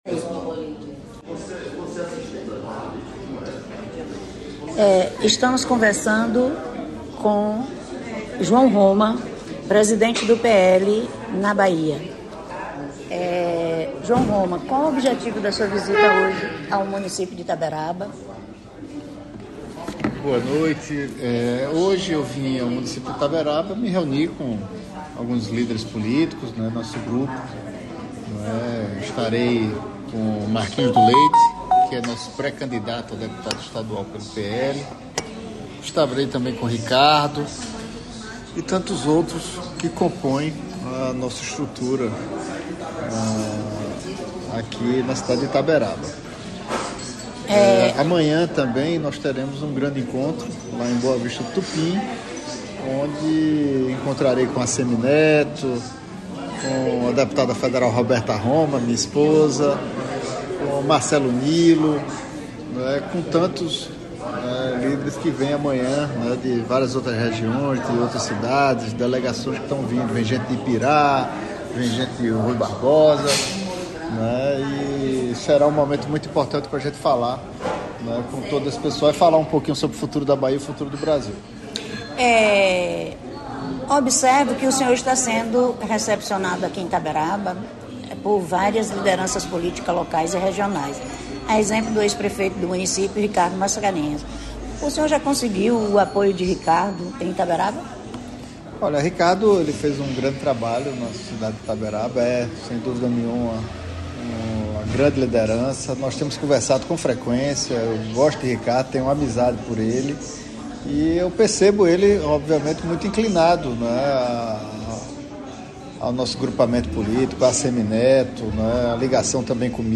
O Jornal da Chapada, por exemplo, fez uma entrevista exclusiva com João Roma (PL).
Uma entrevista com informações sobre as eleições de 2026. Na conversa teve composição da chapa majoritária encabeçada por ACM Neto (UB), da importância de fomento para a região chapadeira, além de ampliar apoios. Considerado um dos ‘braços de ferro” de Bolsonaro, Roma analisa a política nacional sem o seu presidente.